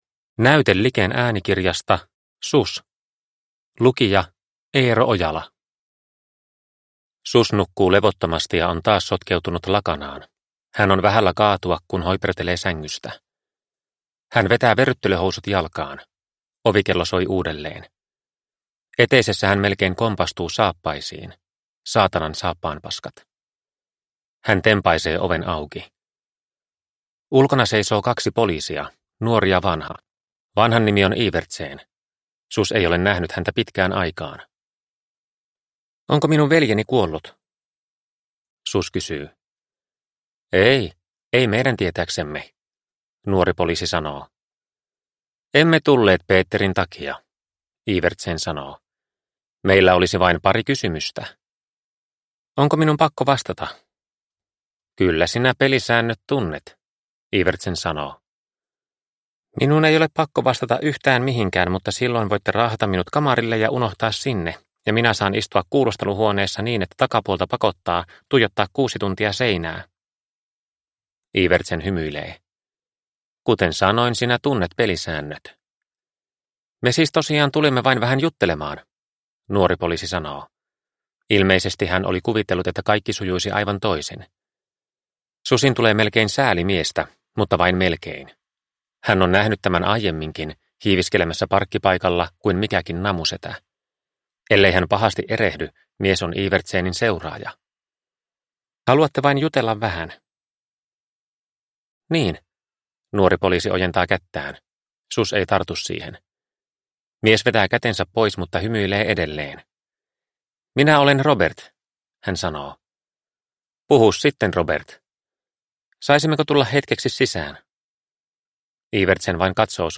Sus – Ljudbok – Laddas ner